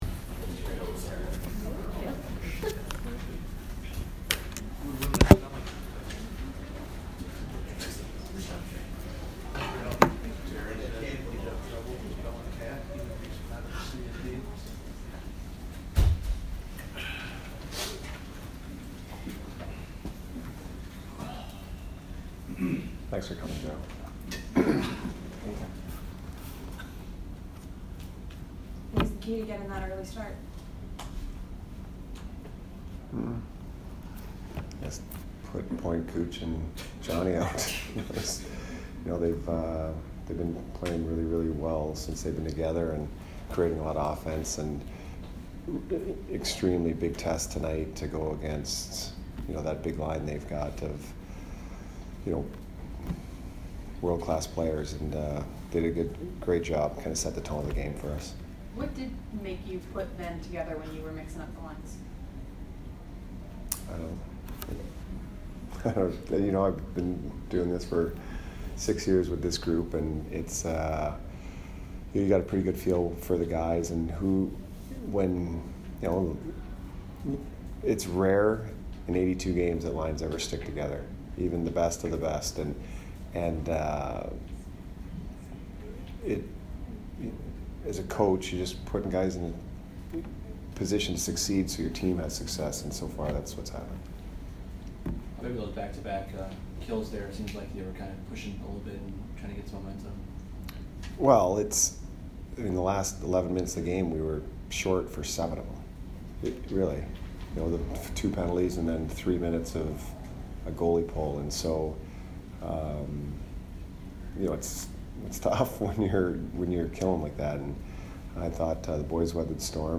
Jon Cooper post-game 11/23